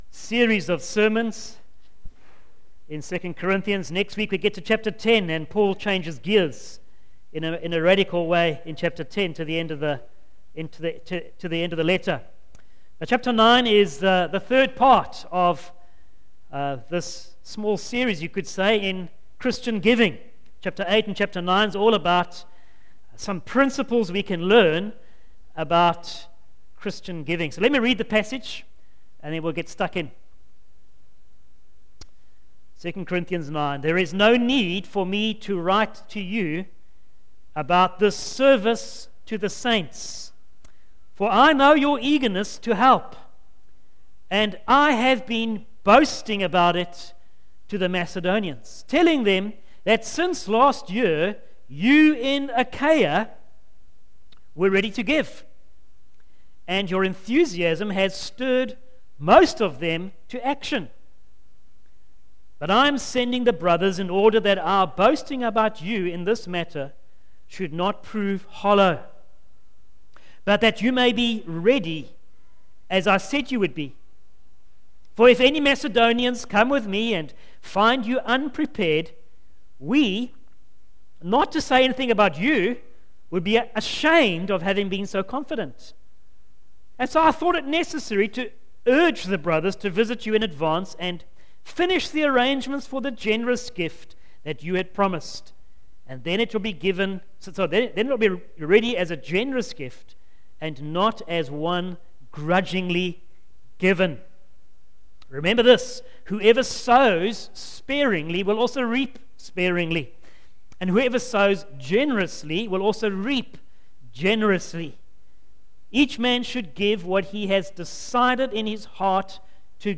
Christian Giving – Part 3 2 Corinthians 8 & 9 Share this... Facebook Twitter email Posted in Morning Service